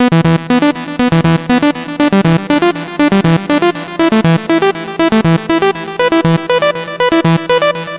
TSNRG2 Lead 004.wav